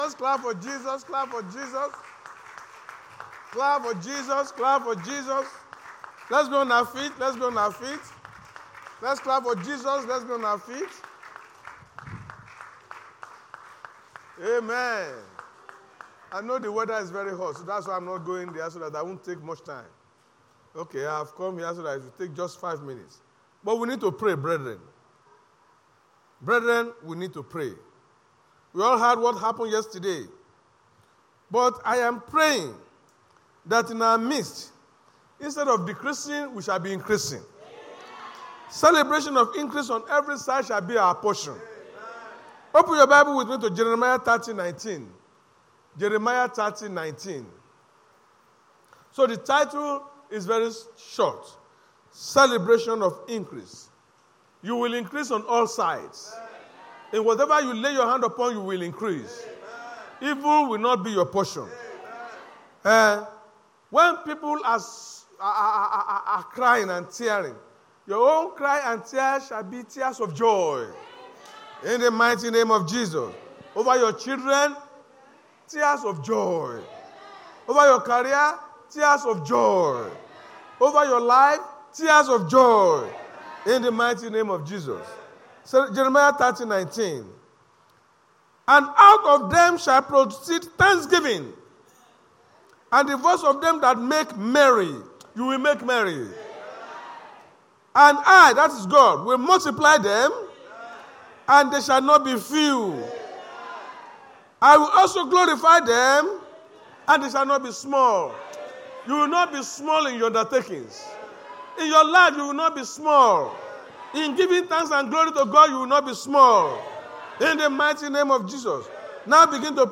A powerful sermon preached by an annointed man of God.
Service Type: Sunday Church Service